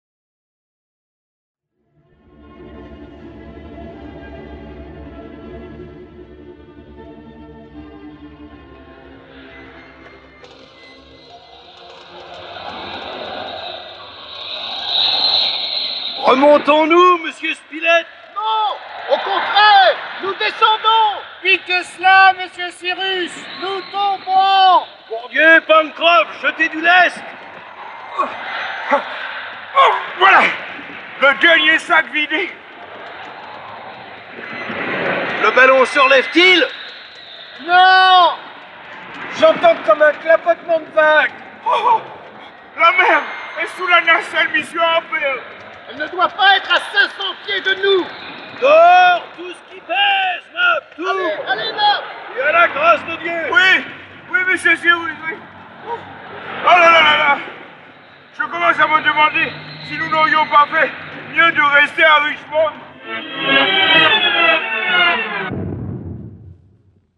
Adaptation audio faite en 1959.